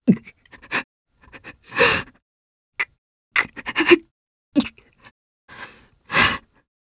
Fluss-Vox_Sad_jp.wav